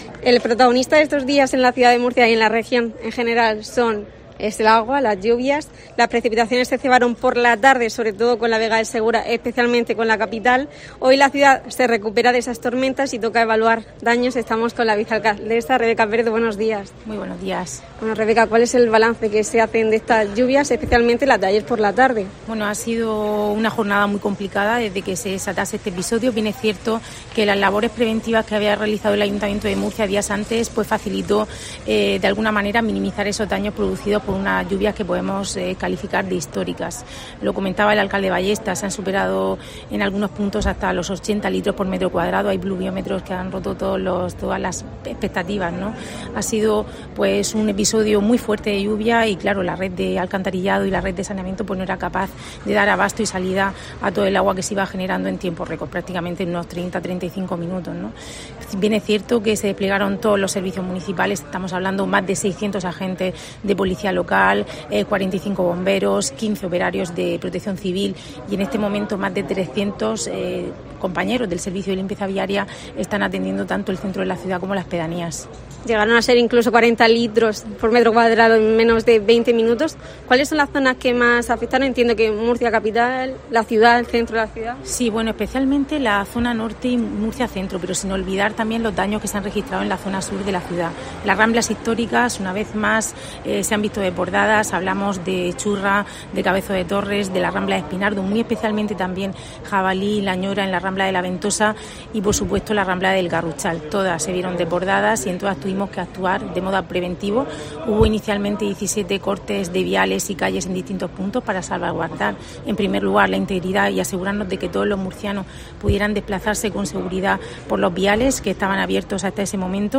Vicealcaldesa de Murcia, Rebeca Pérez, sobre las lluvias en la ciudad